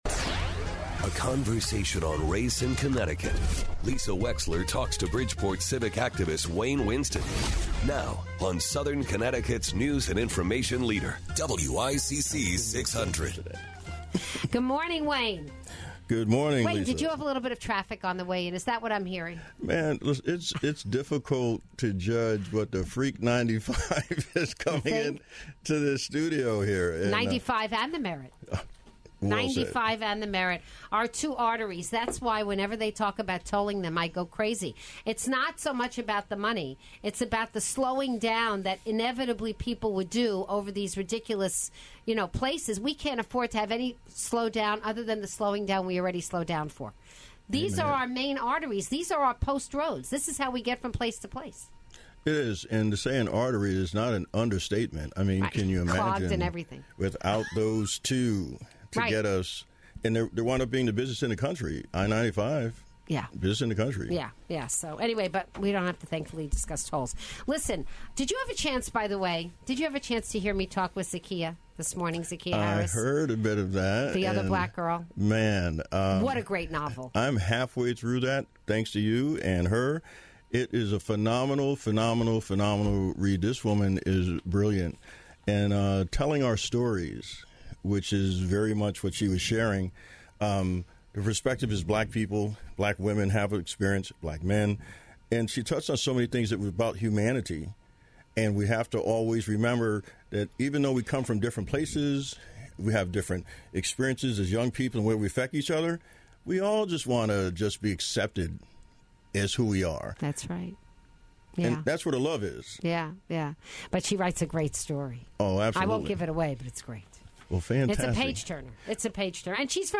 in studio for this week's edition of On Race. How much is white supremacist doctrine permeating the political landscape?